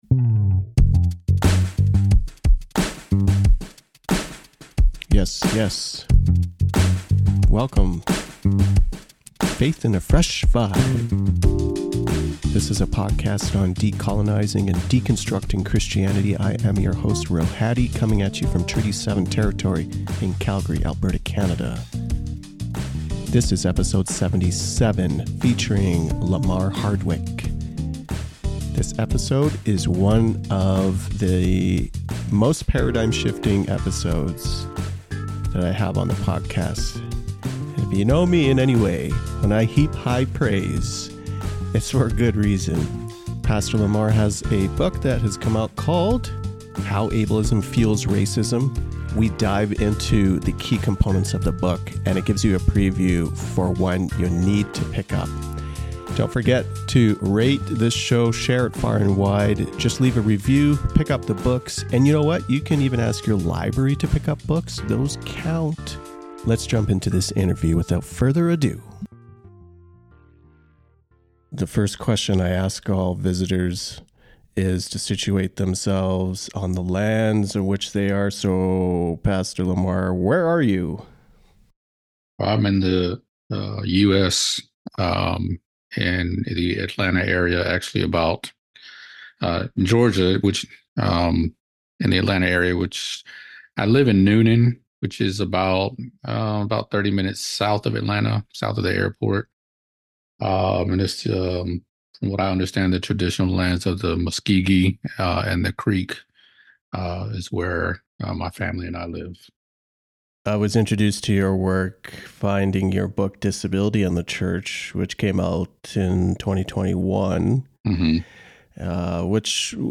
This episode is released as full hour long conversation.